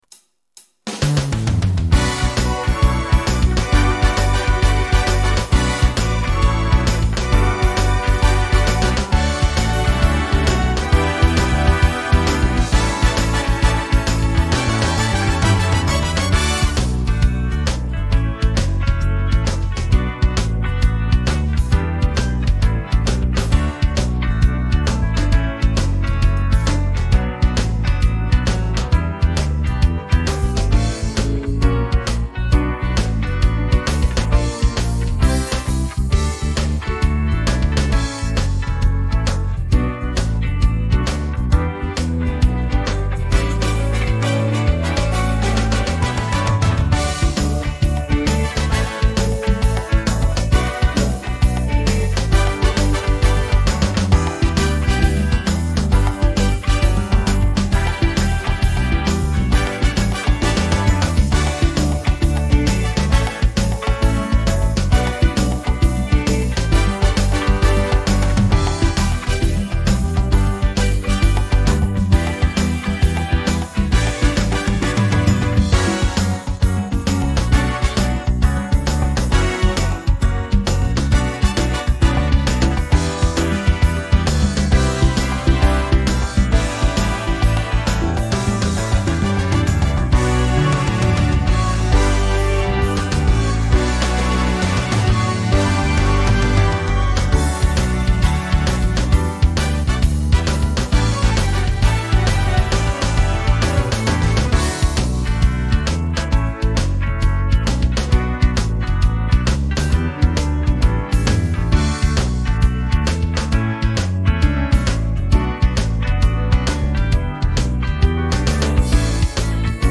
Duc in altum-instrumental.mp3